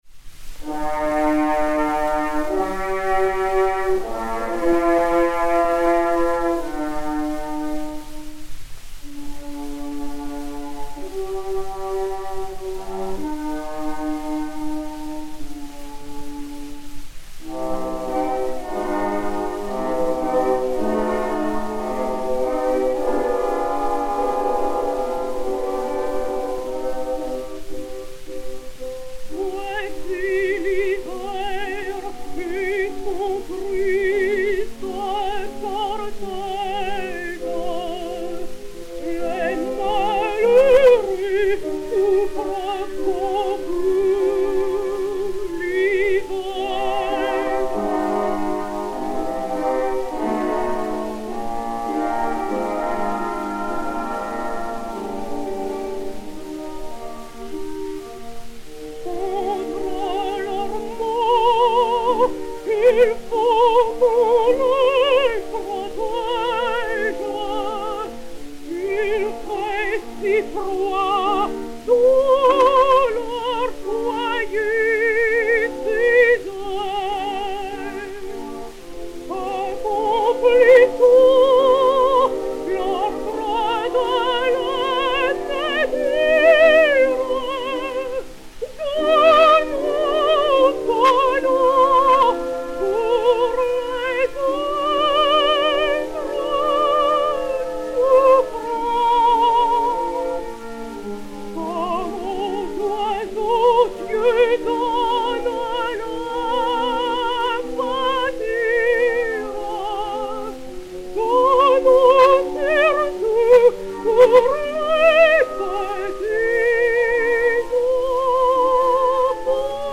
Orchestre
enr. à Paris le 29 novembre 1920